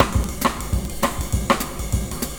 100CYMB07.wav